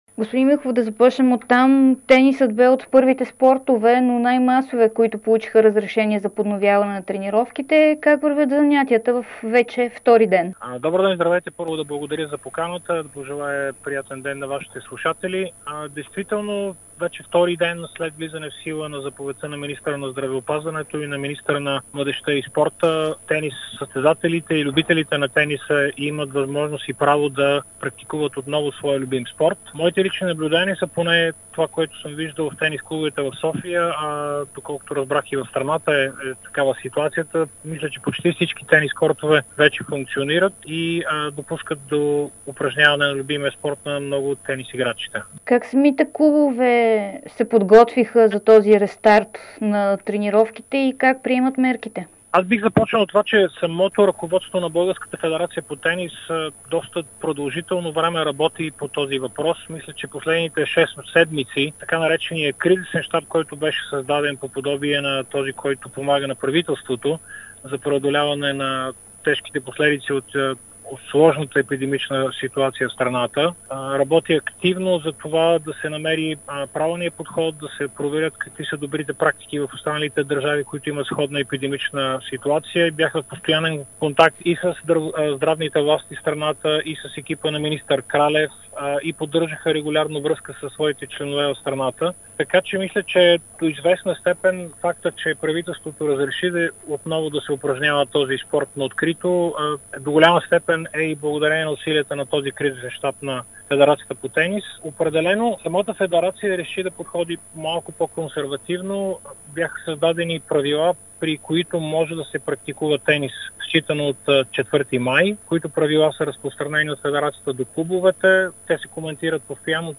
специално интервю за Дарик радио и dsport